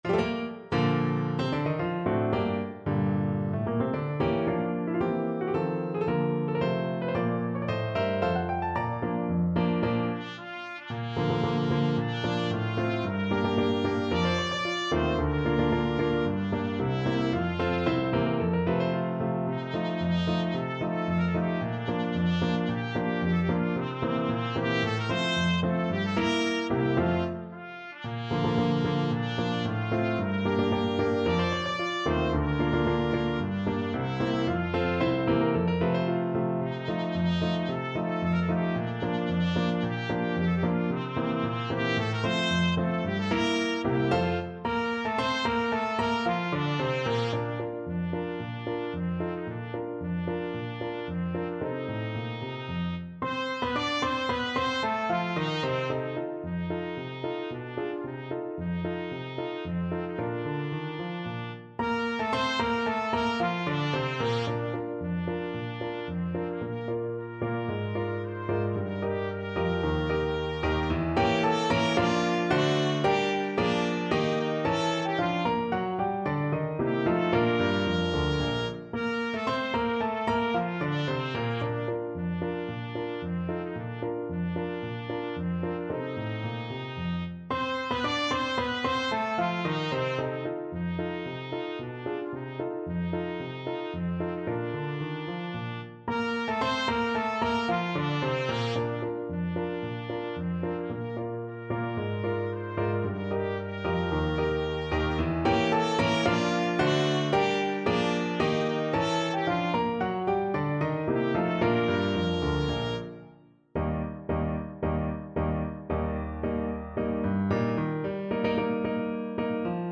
Classical Bagley, Edwin Eugene National Emblem March Trumpet version
Play (or use space bar on your keyboard) Pause Music Playalong - Piano Accompaniment Playalong Band Accompaniment not yet available transpose reset tempo print settings full screen
2/2 (View more 2/2 Music)
Bb major (Sounding Pitch) C major (Trumpet in Bb) (View more Bb major Music for Trumpet )
March =c.112
Trumpet  (View more Intermediate Trumpet Music)
Classical (View more Classical Trumpet Music)